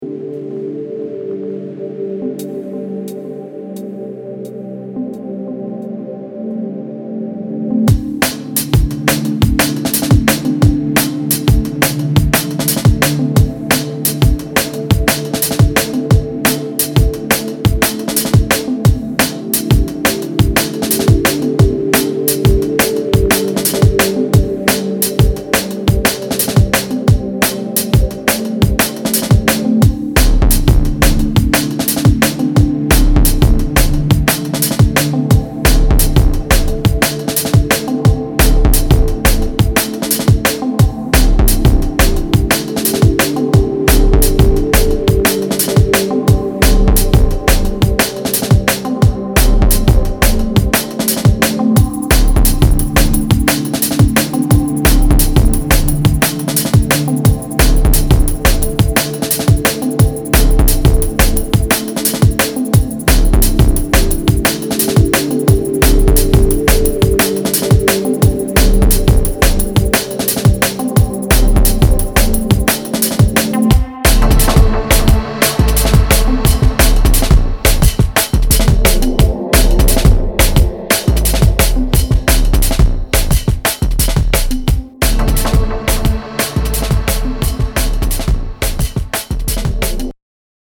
Styl: Drum'n'bass, Jungle/Ragga Jungle